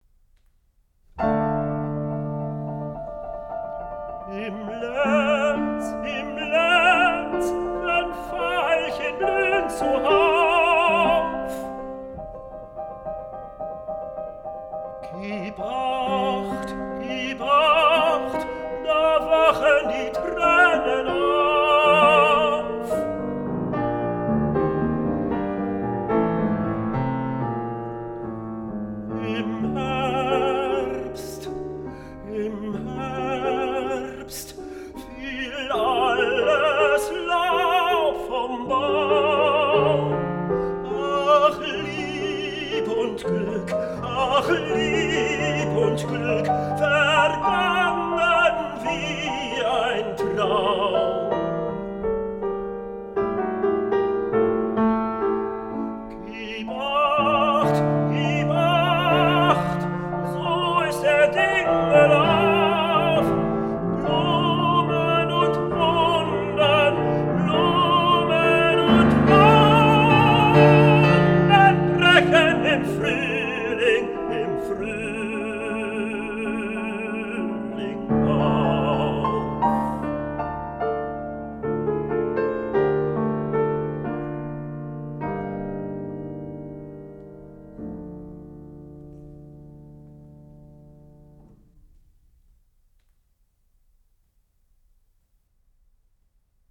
üppige Klangwelt des österreichischen Impressionismus